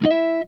OCTAVE 4.wav